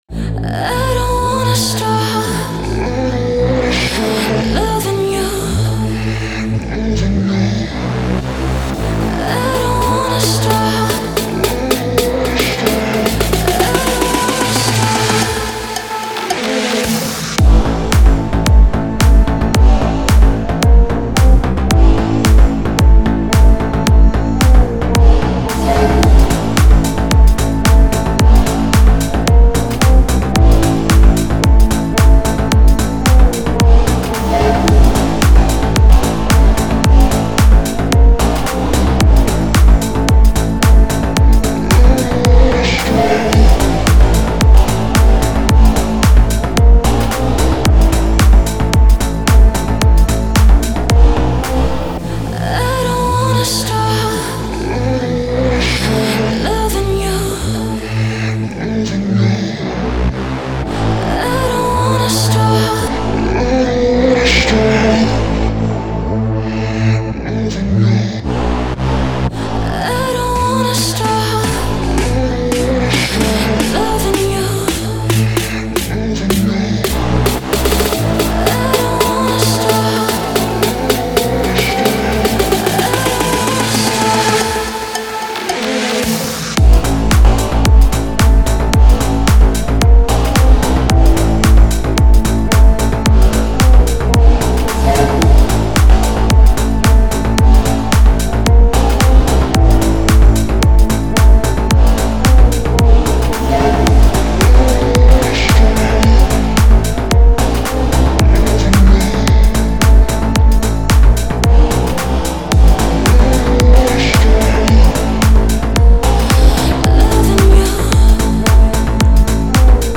это зажигательный трек в жанре R&B и поп